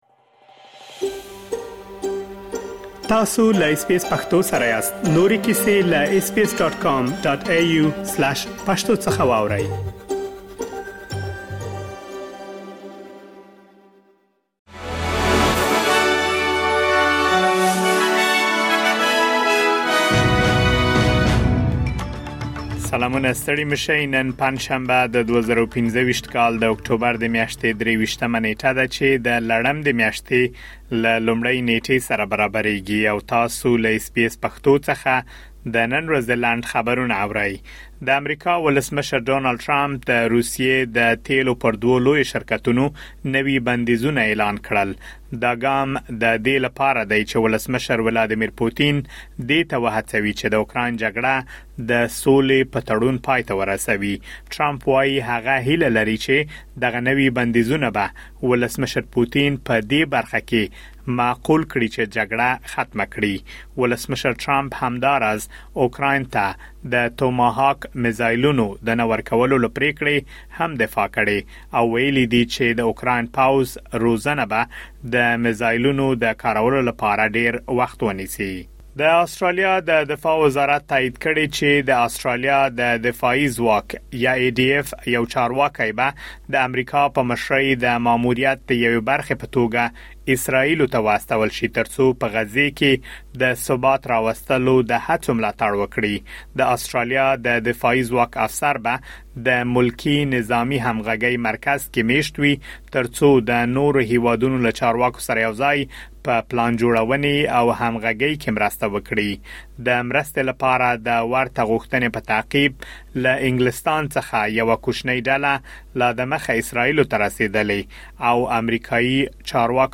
د اس بي اس پښتو د نن ورځې لنډ خبرونه دلته واورئ.